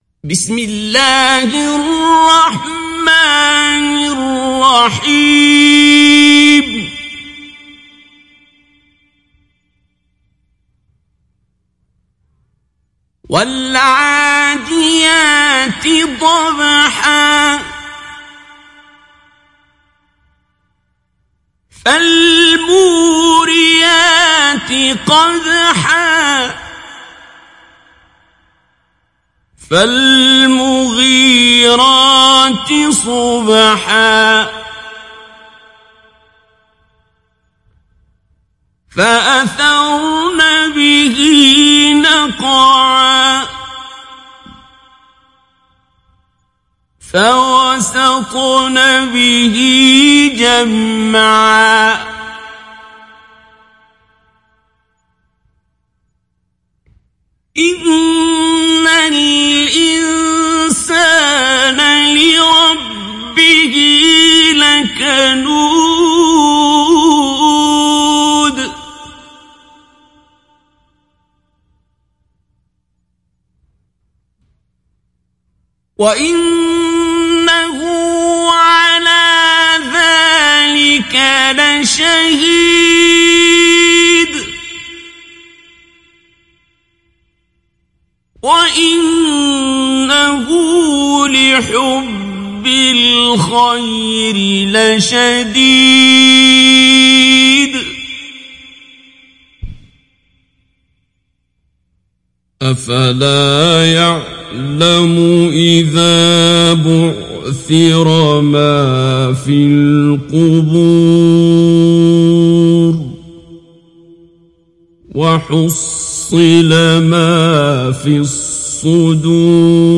دانلود سوره العاديات mp3 عبد الباسط عبد الصمد مجود روایت حفص از عاصم, قرآن را دانلود کنید و گوش کن mp3 ، لینک مستقیم کامل
دانلود سوره العاديات عبد الباسط عبد الصمد مجود